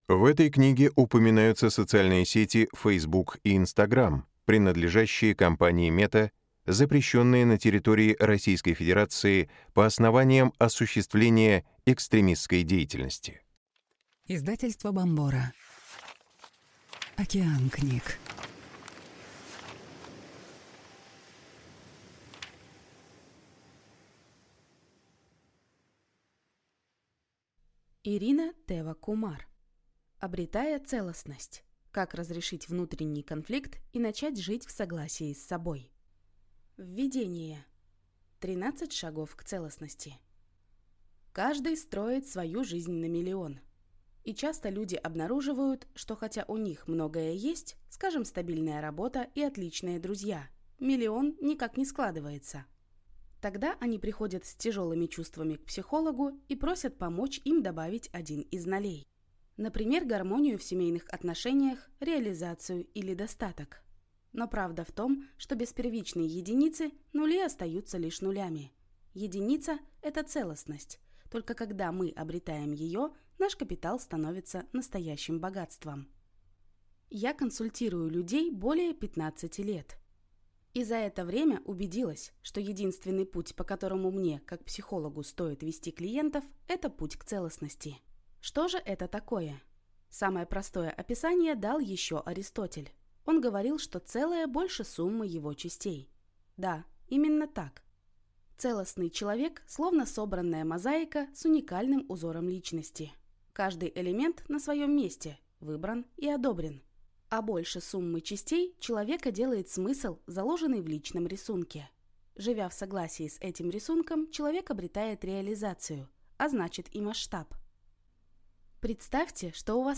Аудиокнига Обретая целостность. Как разрешить внутренний конфликт и начать жить в согласии с собой | Библиотека аудиокниг